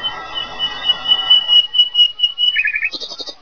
oiseau30.wav